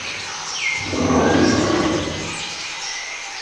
se-jungle.wav